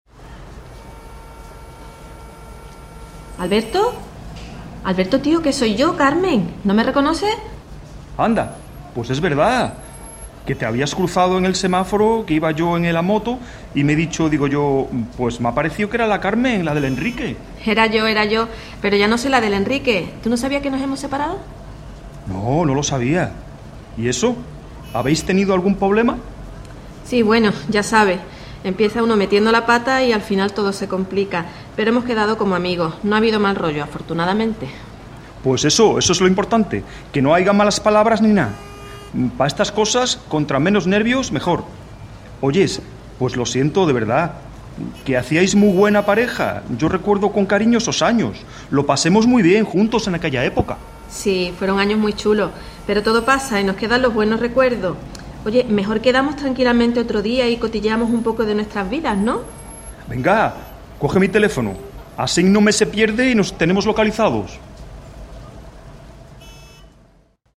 Te traemos de nuevo la conversación informal sobre la que trabajamos en la tarea anterior. En ella uno de los hablantes utiliza la variante dialectal propia de Andalucía.